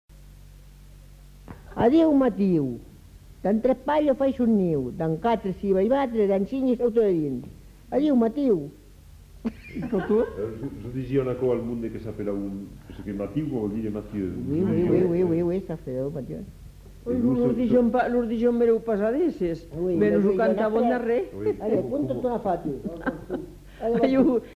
Aire culturelle : Haut-Agenais
Effectif : 1
Type de voix : voix de femme
Production du son : récité
Classification : formulette